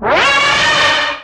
caulthit1.ogg